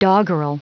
Prononciation du mot doggerel en anglais (fichier audio)
Prononciation du mot : doggerel